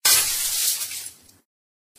* glass related sounds
* Downmix stereo effects to mono
glass_break1.ogg